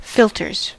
filters: Wikimedia Commons US English Pronunciations
En-us-filters.WAV